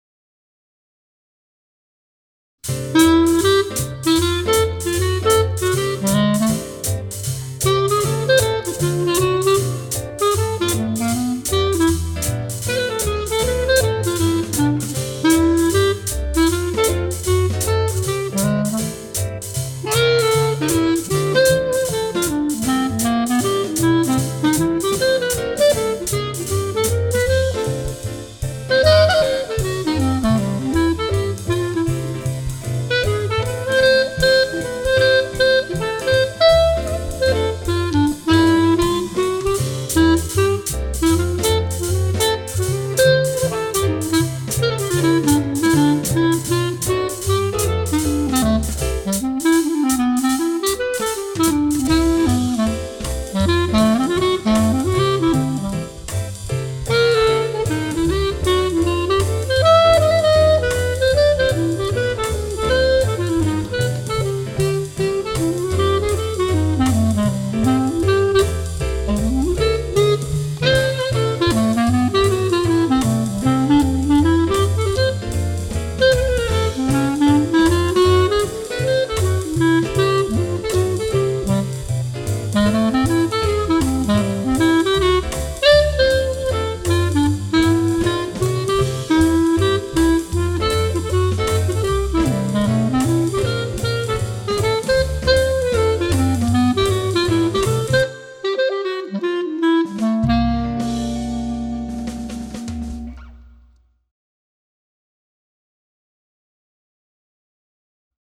Jazz Improvisation